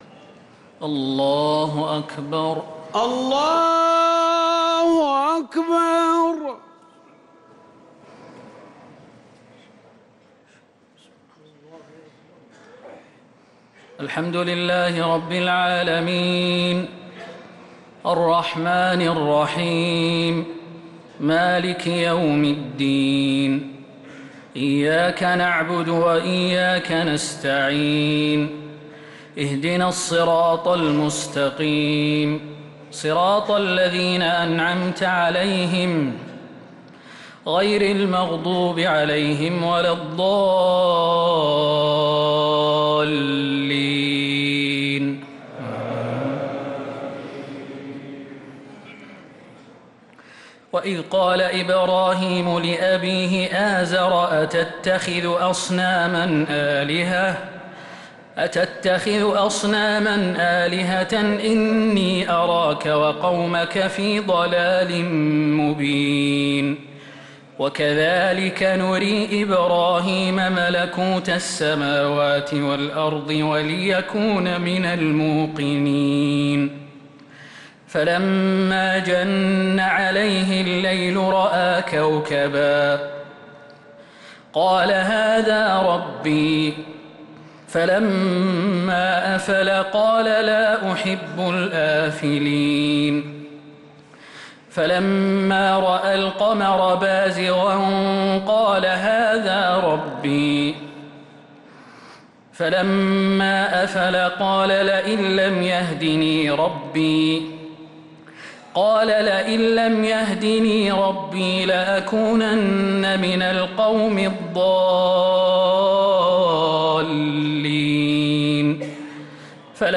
تراويح ليلة 10 رمضان 1446هـ من سورة الأنعام (74-135) | taraweeh 10th niqht ramadan1446H Surah Al-Anaam > تراويح الحرم النبوي عام 1446 🕌 > التراويح - تلاوات الحرمين